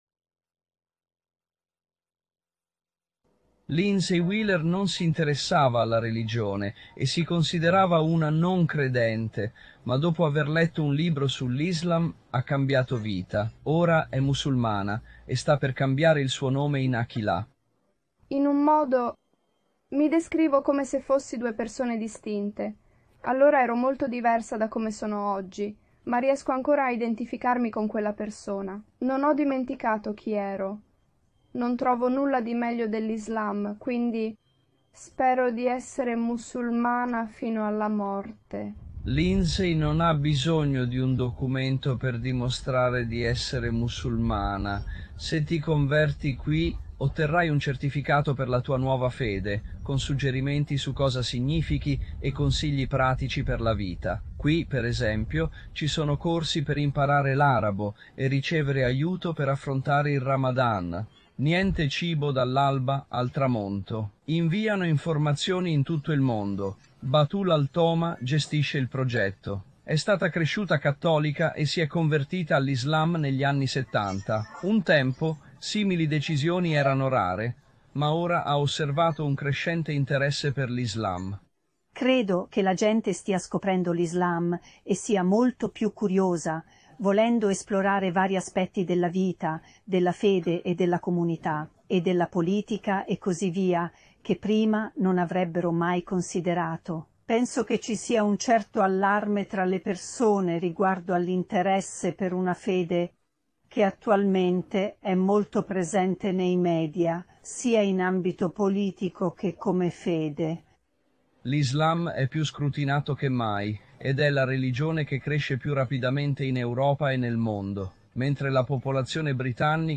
Descrizione: In questa trasmissione di 5 News, i giornalisti spiegano come l'Islam sia diventata la religione in più rapida crescita nel Regno Unito e in Europa.